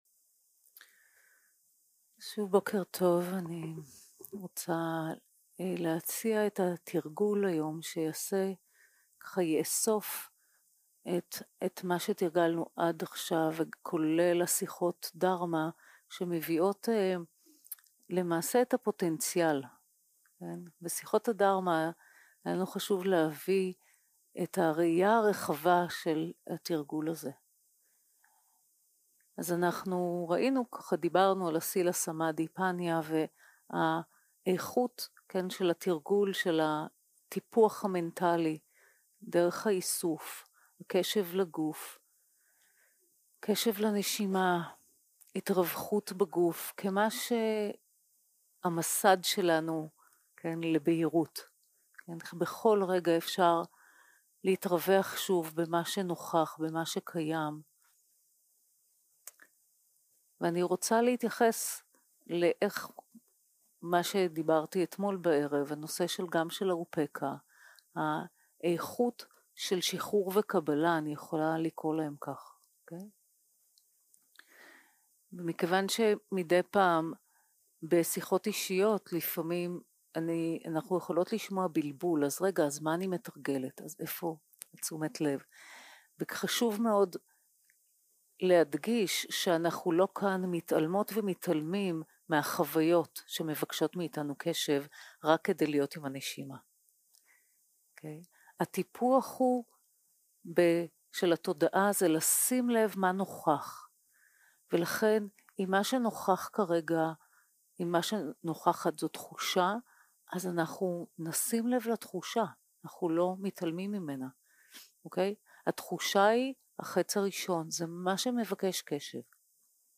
יום 4 - הקלטה 8 - בוקר - הנחיות למדיטציה - התרווחות וקבלה של מה שנוכח 0:00 0:00 סוג ההקלטה: Dharma type: Guided meditation שפת ההקלטה: Dharma talk language: Hebrew